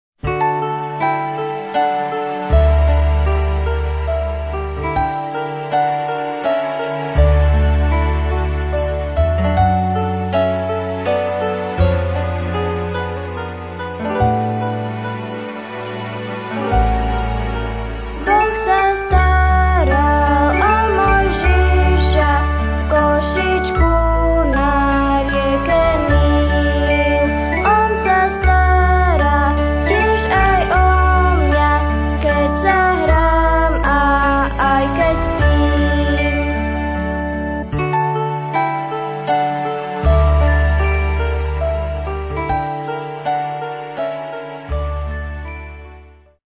piesne – noty,